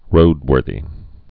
(rōdwûrthē)